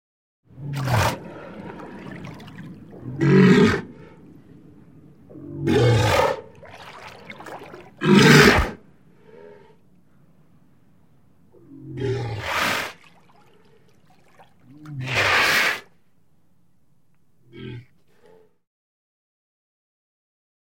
Морж плещется в воде и кряхтит